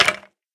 skeletonhurt4.ogg